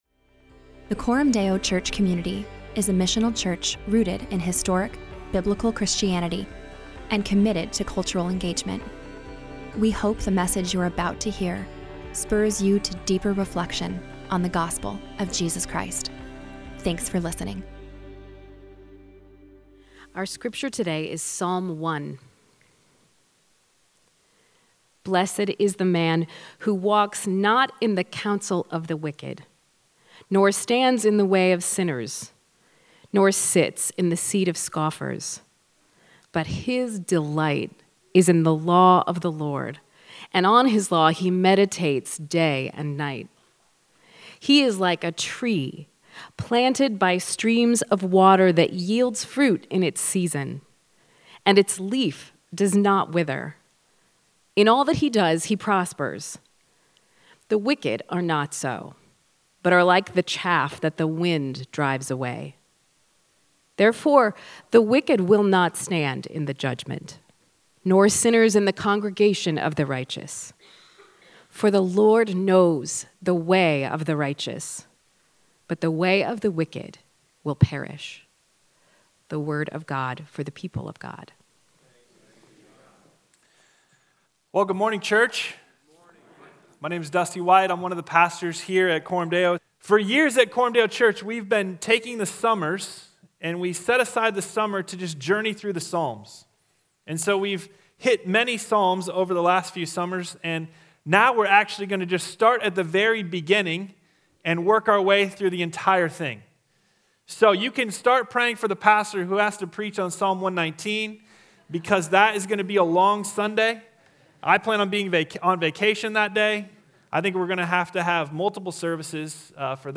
The Christian life is a WAY, not merely a worldview. In this sermon, we consider what it means to turn from wickedness and walk with Jesus in the way of the righteous.